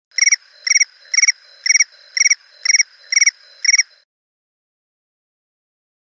Crickets.mp3